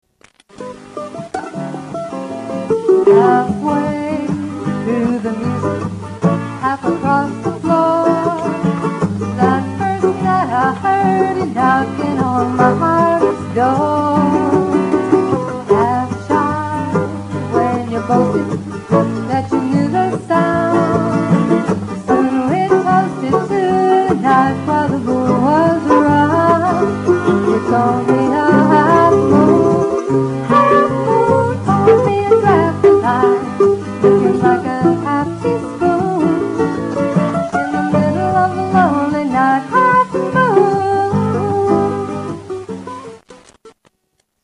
vocals, guitar, washboard and bones
mandolin and dobro